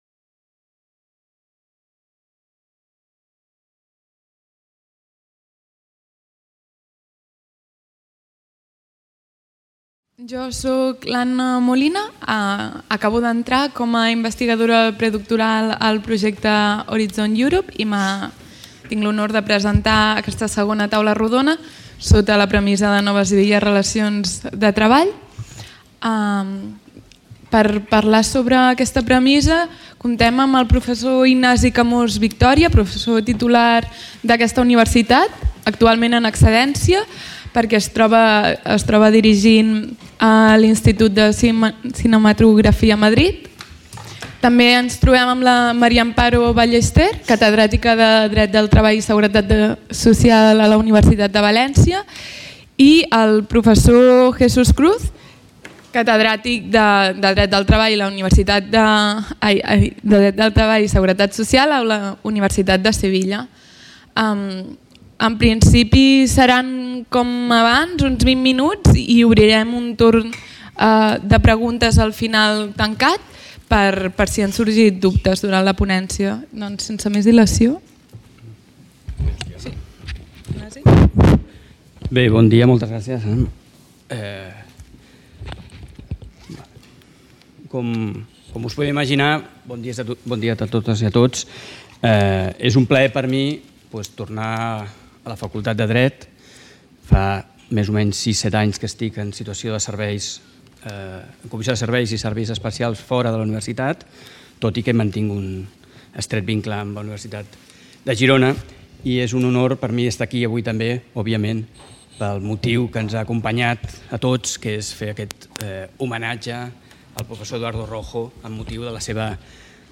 Segona taula: noves i velles relacions de treball